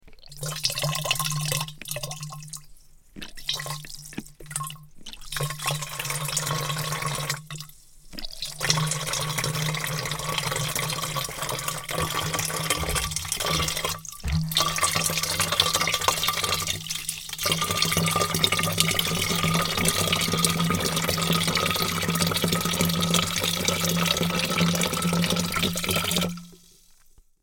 دانلود آهنگ آب 80 از افکت صوتی طبیعت و محیط
دانلود صدای آب 80 از ساعد نیوز با لینک مستقیم و کیفیت بالا
جلوه های صوتی